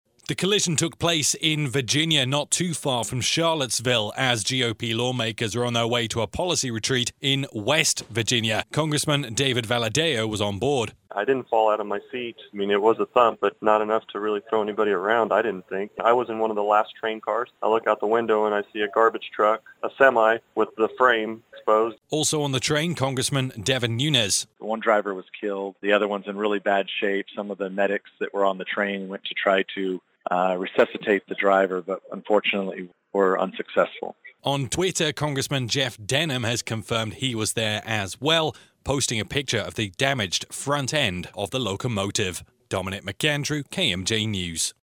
as it aired